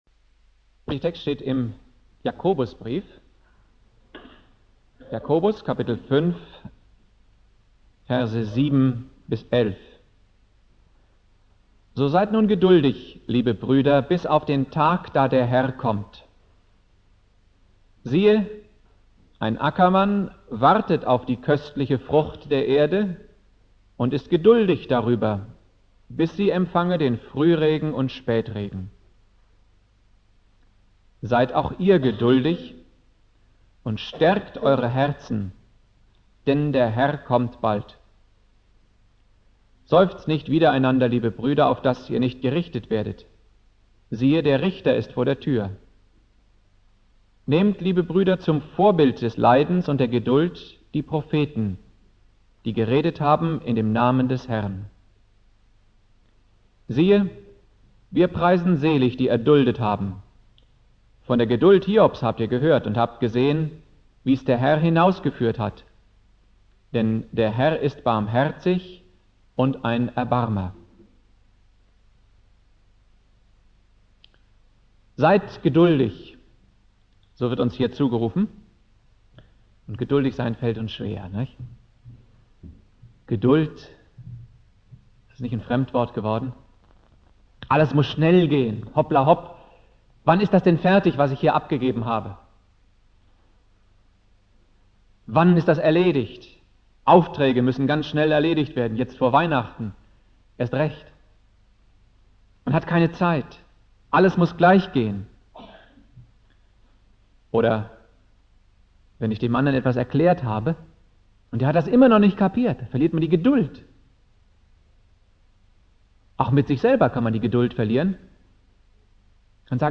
Predigt
2.Advent